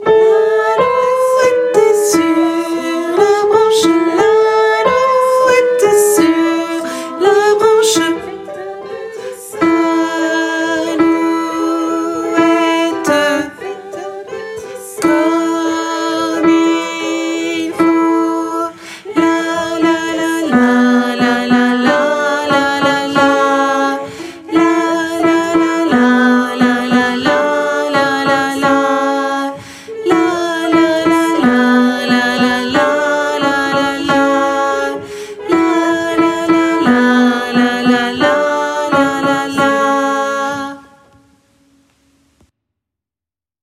- Œuvre pour choeur à 4 voix mixtes (SATB)
- chanson populaire de Lorraine
MP3 versions chantées
Alto et autres voix en arrière-plan